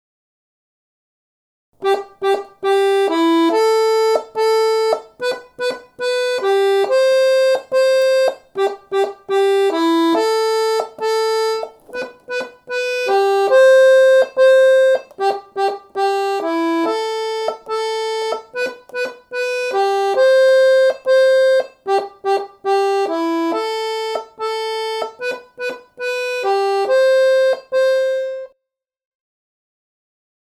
melodia_parte_2.mp3